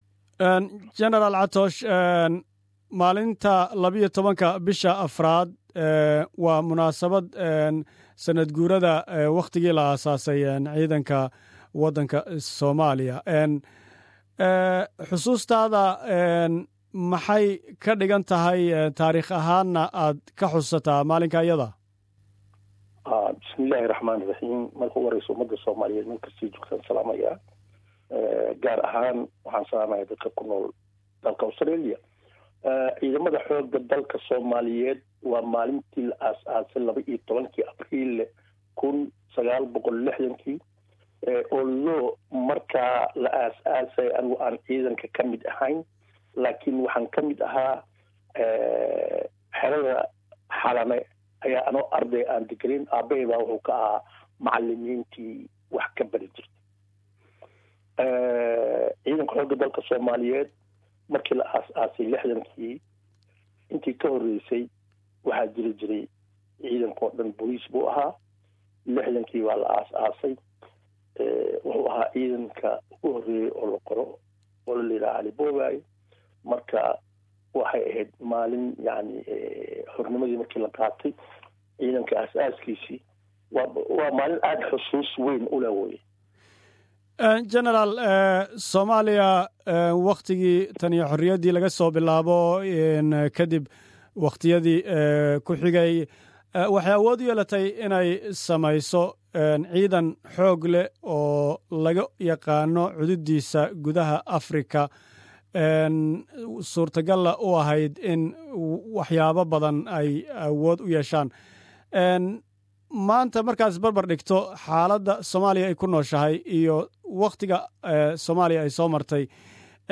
Janaral Maxamed Catoosh, oon ka warysanay sanad guurada Ciidanka Xooga Dalka Soomaalia.